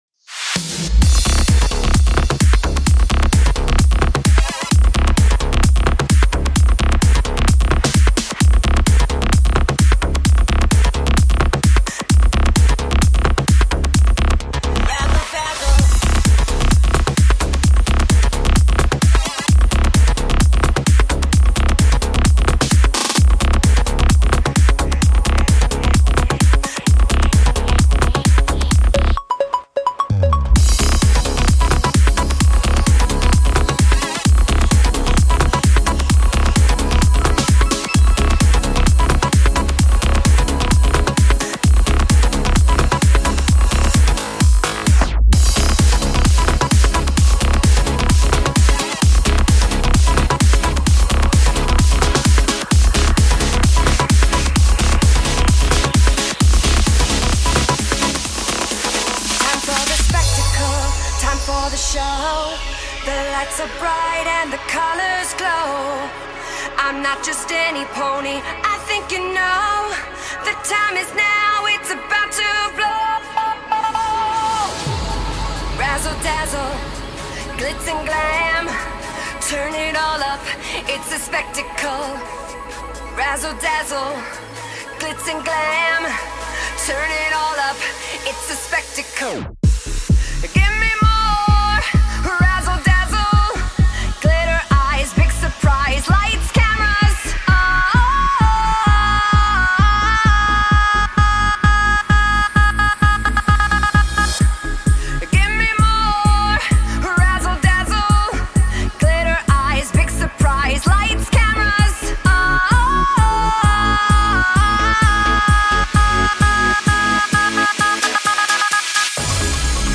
I thought this needed some Prog vibes.
Hardware synth information
main saw lead
high arpeggio
muted guitar